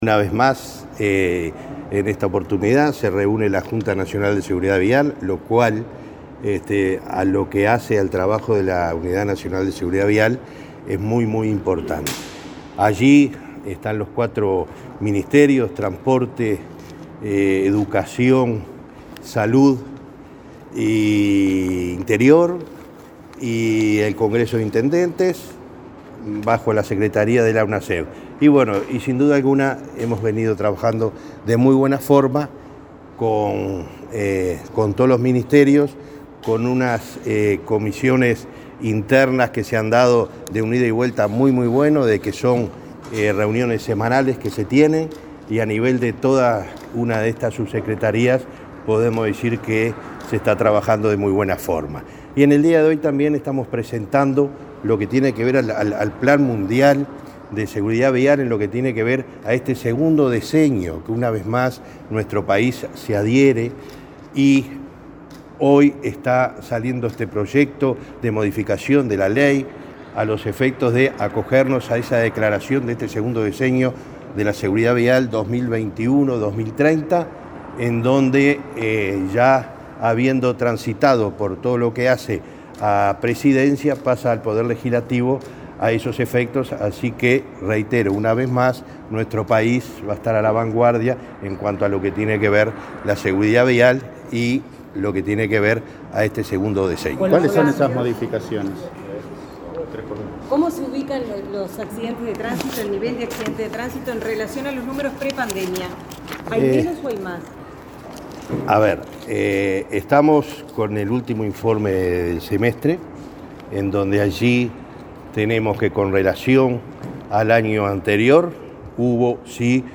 Reunión de la Junta Nacional de Seguridad Vial 30/11/2021 Compartir Facebook X Copiar enlace WhatsApp LinkedIn La Junta Nacional de Seguridad Vial se reunió este martes 30 en la Torre Ejecutiva para realizar el cierre del año. El presidente de la Unidad Nacional de Seguridad Vial (Unasev), Alejandro Draper, informó a la prensa sobre el alcance del encuentro.